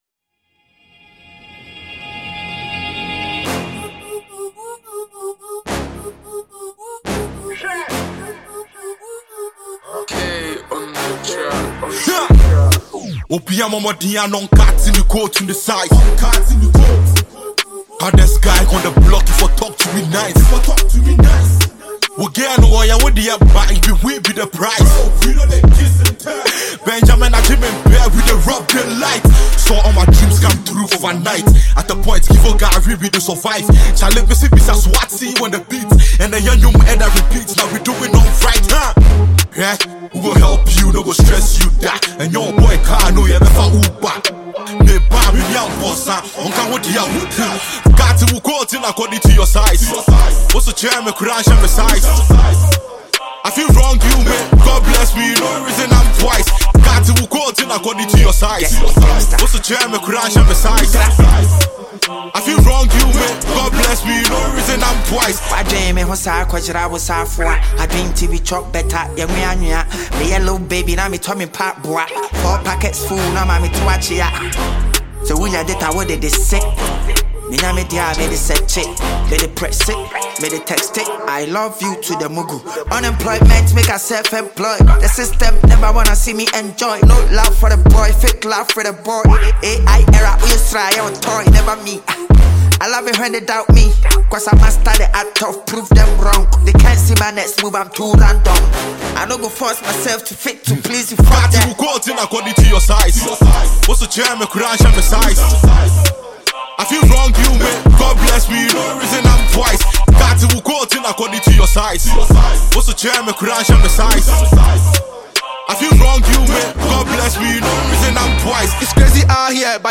With its playful message and infectious flow